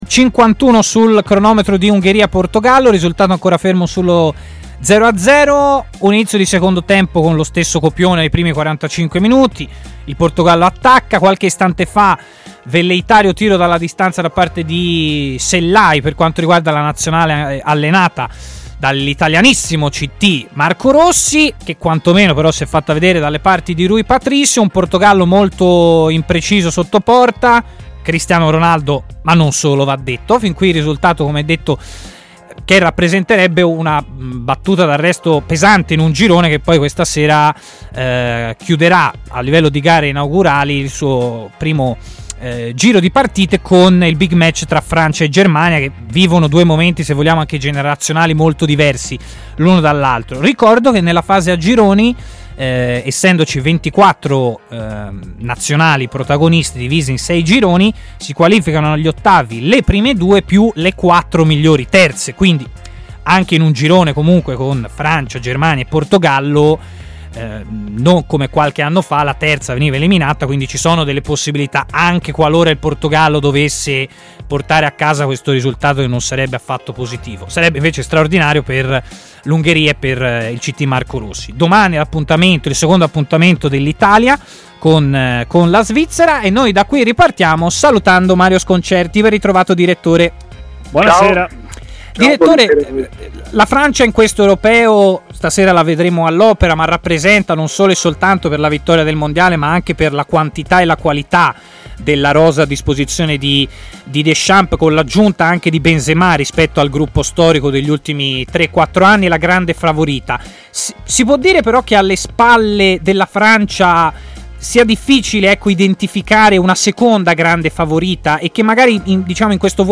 decano del giornalismo sportivo italiano e opinionista di TMW Radio, è intervenuto durante Stadio Aperto